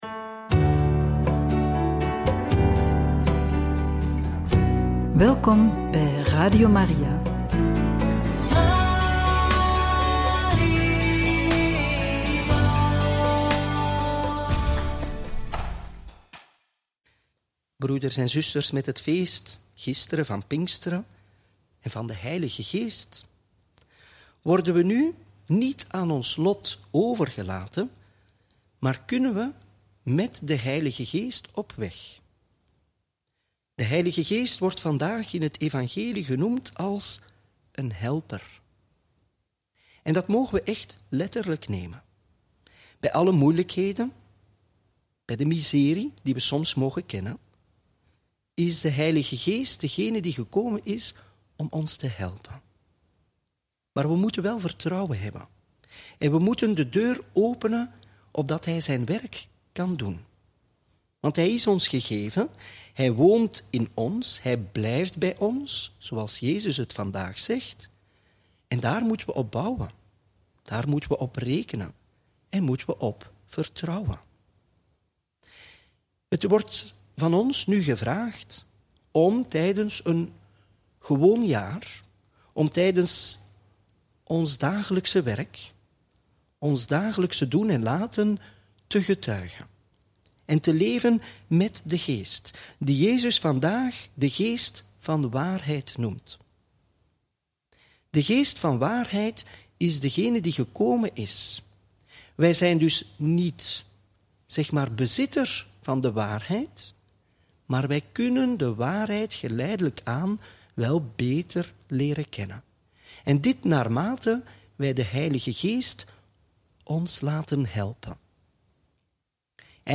Homilie op Pinkstermaandag, feest van Maria, Moeder van de Kerk – Joh. 19, 25-34